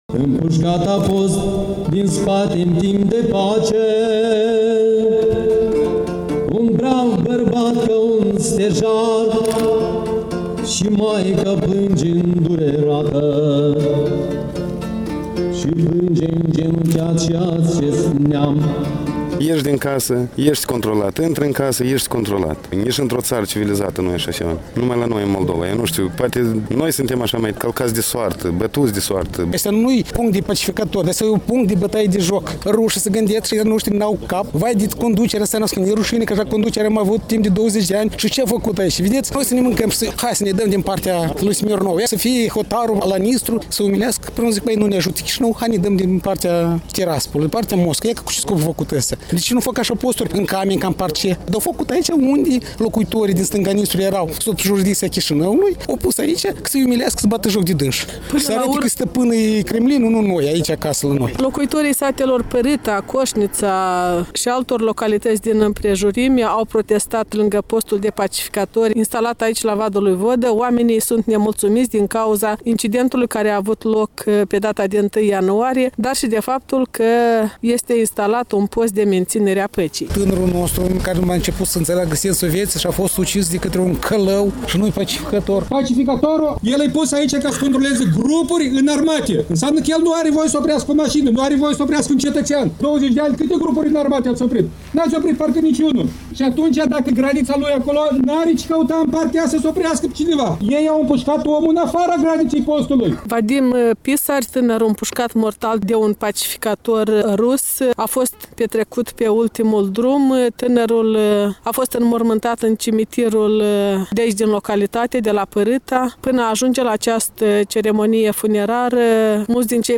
Un reportaj de la funeraliile şi protestul de la Pârâta